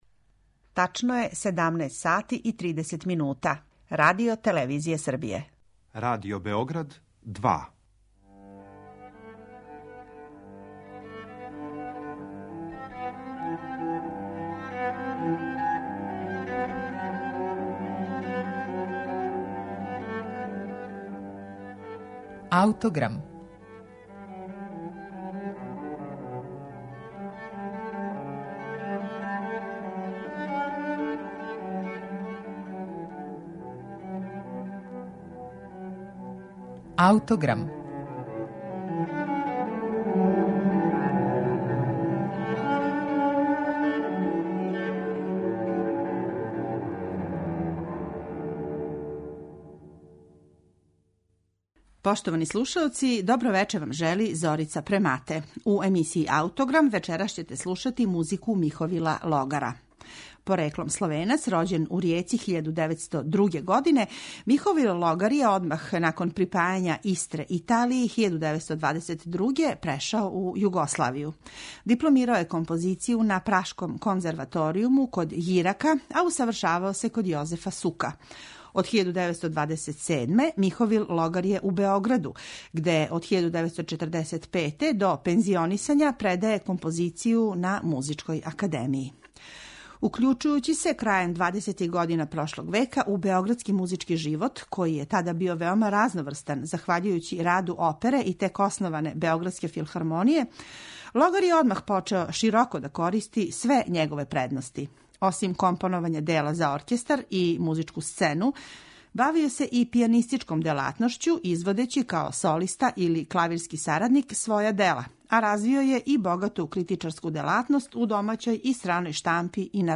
Његов ведри неокласицизам, обогаћен примесама импресионистичког колорита, приказаће Четврта свита из балета „Златна рибица". На нашем архивском снимку свира СО РТВ Љубљана, а диригује Само Хубад.